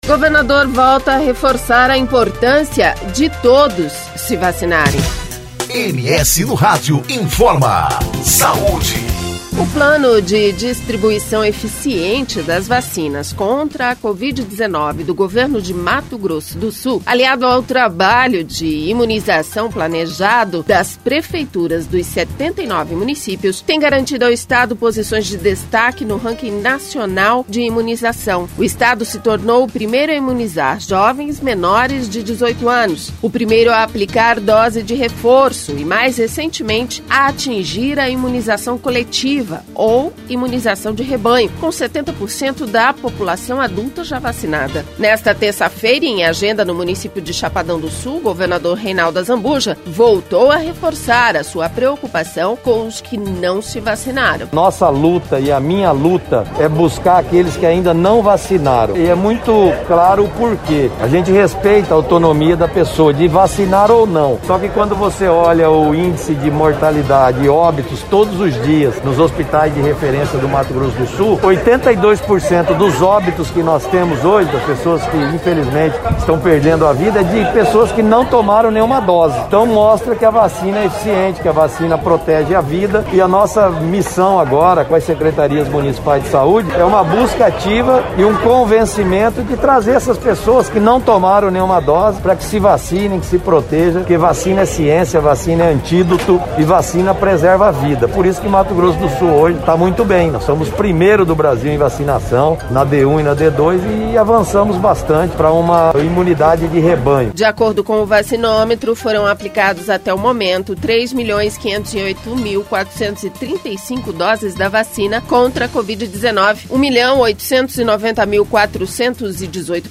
Nesta terça-feira, em agenda no município de Chapadão do Sul, o governador Reinaldo Azambuja voltou a reforçar a sua preocupação com os que não vacinaram.